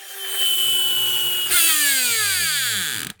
• toy spring motor airplane stop.wav
Recorded with a Steinberg Sterling Audio ST66 Tube, in a small apartment studio.
toy_spring_motor_airplane_stop_Qlp.wav